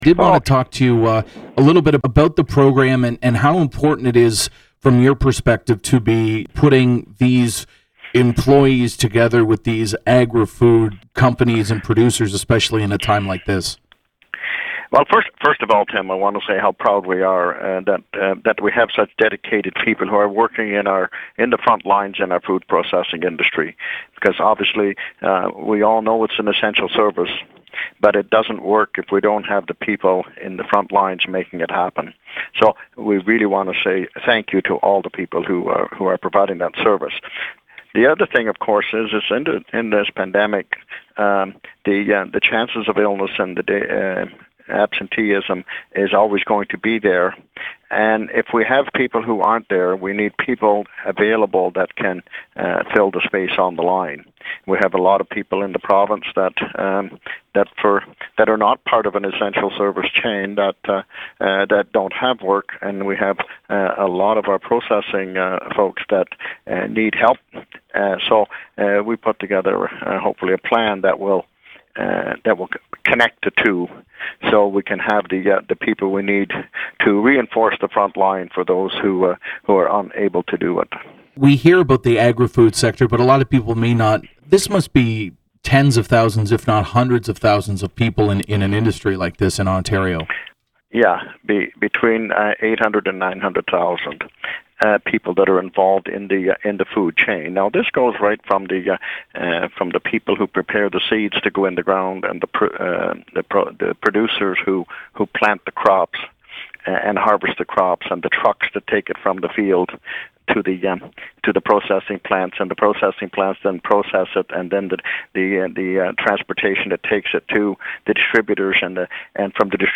Minister-Ernie-Hardiman.mp3